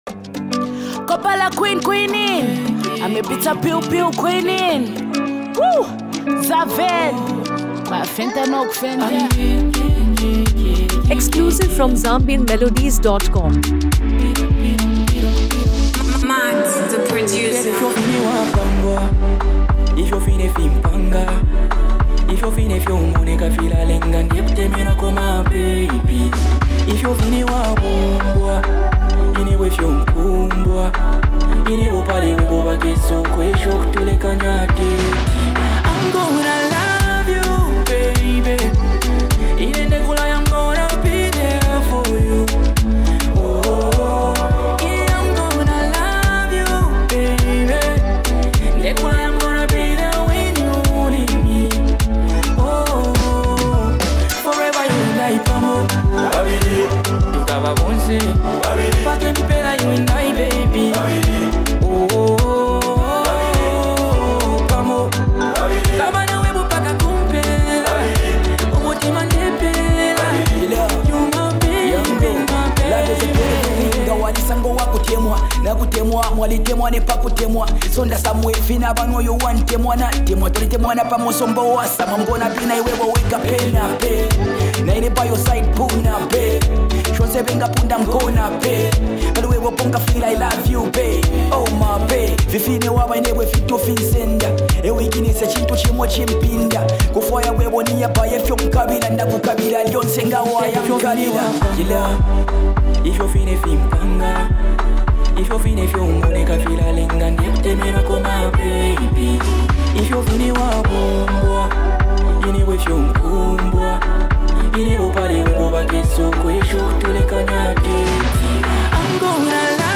heartfelt love song
powerful vocals
Zambian R&B Love Anthem
clean production, and strong vocal chemistry.